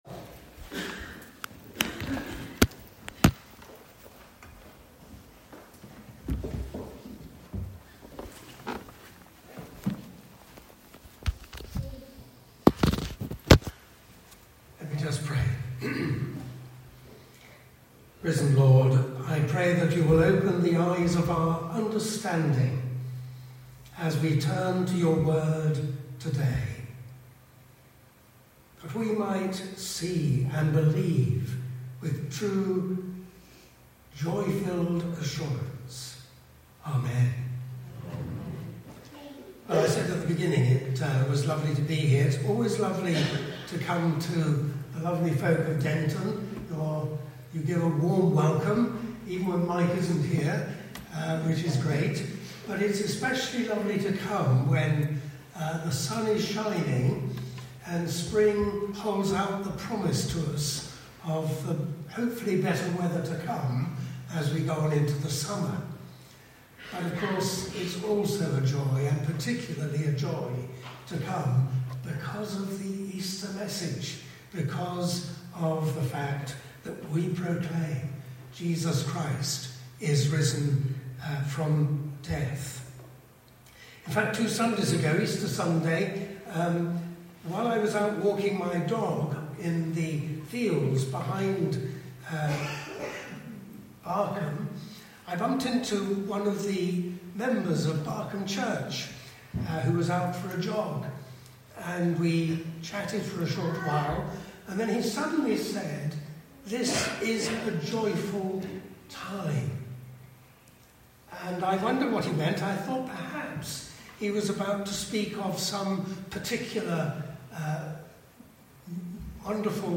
SERMON-4TH-MAY-2025.mp3